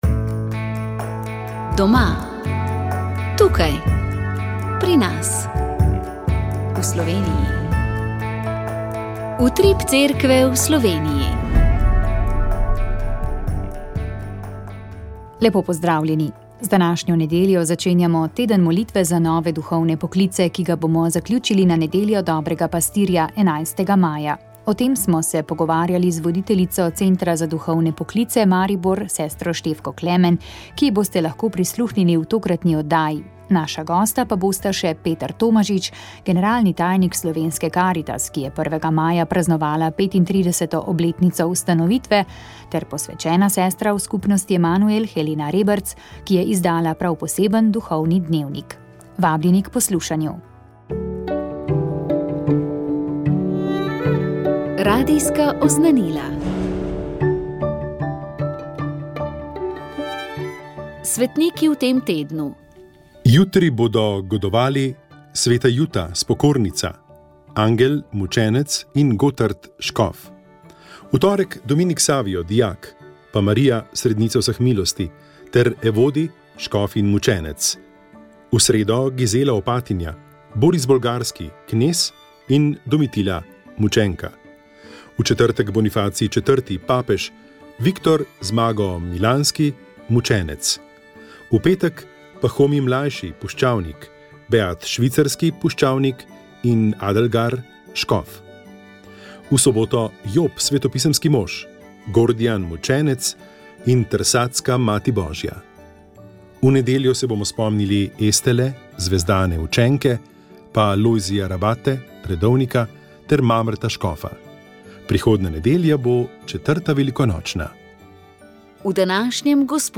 Z njim smo se pogovarjali o spominih na mladost v Savinjski dolini, o slikarstvu in restavratorstvu. Kakšno besedo smo namenili tudi teku, kajti večkrat je pretekel originalni maraton v Grčiji.